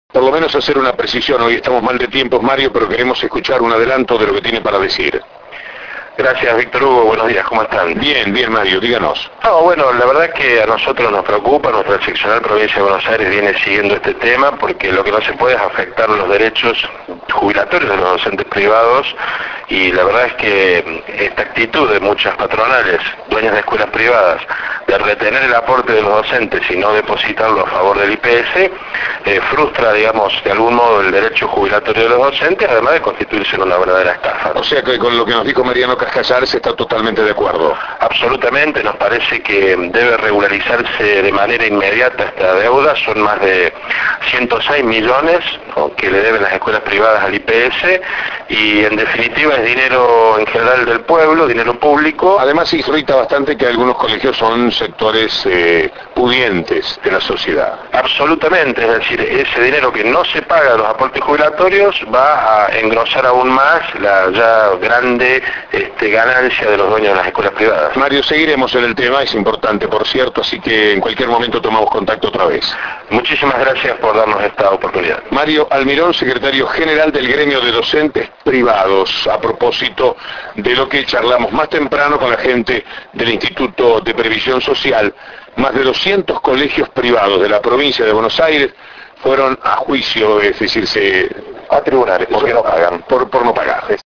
en una entrevista realizada por el periodista Víctor Hugo Morales, en su programa radial “La Mañana” (AM 590). Además, el dirigente Sindical aclaró que las escuelas privadas le deben alrededor de 106 millones al IPS.